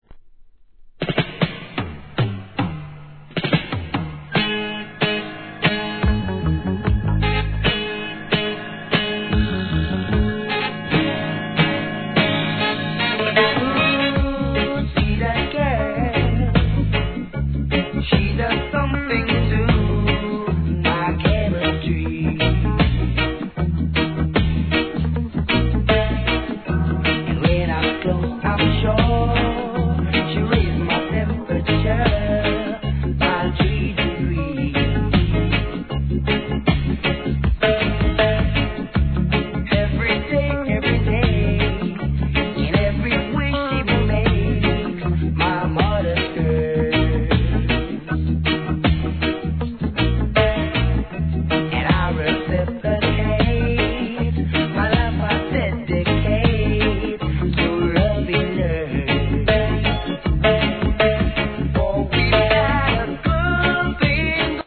REGGAE
英国録音も多数でPOPなイメージが◎